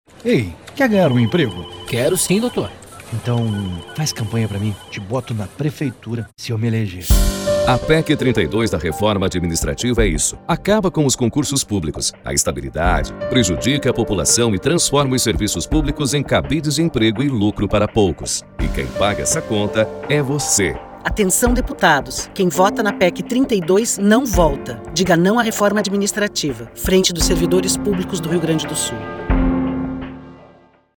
Campanha de rádio contra a PEC 32 mostra político negociando cargos - Sintergs
A conversa faz parte de spot de rádio em defesa do serviço público contra a PEC 32, que começou a ser veiculada na terça-feira (14/9) em 38 emissoras da Capital e do Interior do Rio Grande do Sul.